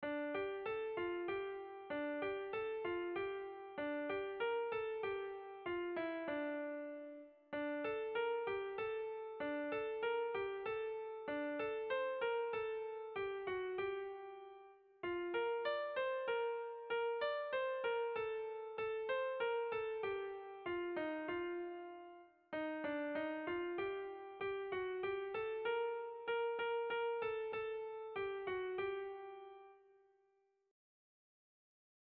Sentimenduzkoa
Zortziko handia (hg) / Lau puntuko handia (ip)
10 / 8A / 10 / 8A / 10 / 8A / 10 / 8A (hg) | 18A / 18A /18A / 18A (ip)
ABDE